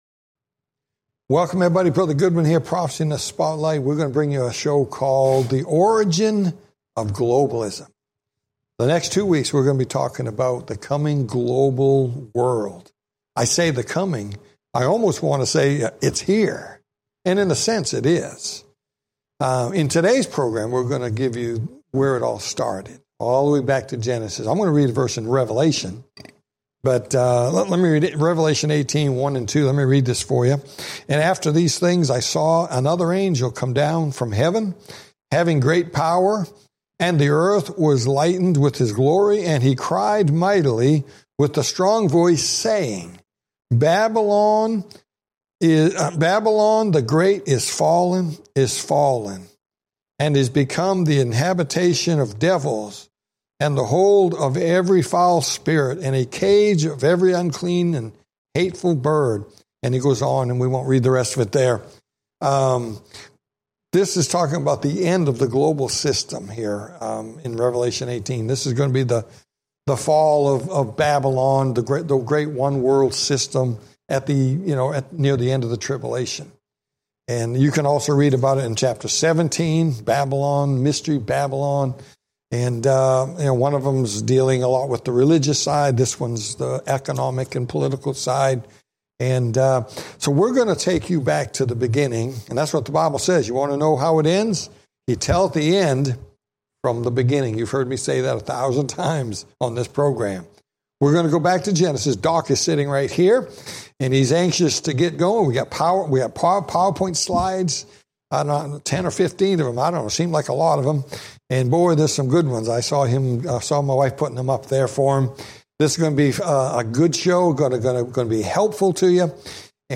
Talk Show Episode, Audio Podcast, Prophecy In The Spotlight and Origins Of Globalism, and The Middle East In Bible Prophecy on , show guests , about Origins Of Globalism,The Middle East In Bible Prophecy, categorized as History,News,Politics & Government,Religion,Society and Culture,Theory & Conspiracy